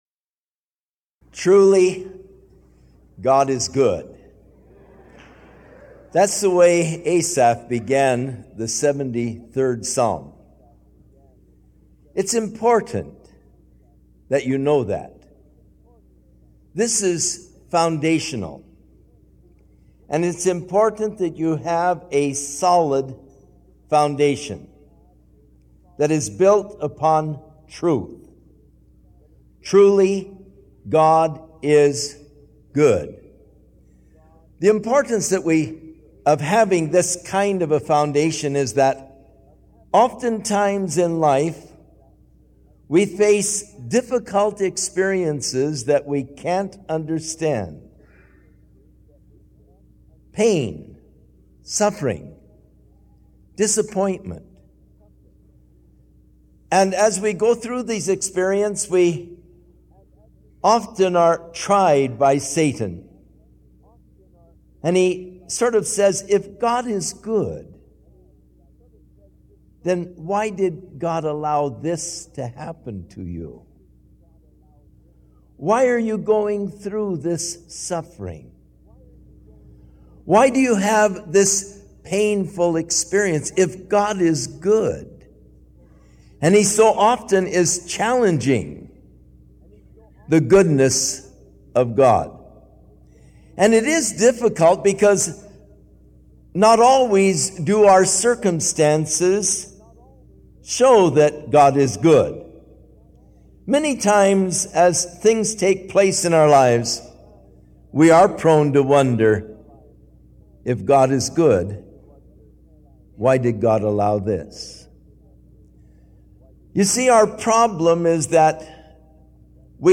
A verse-by-verse sermon through Psalms 73 by Pastor Chuck Smith with commentary, notes, and outlines.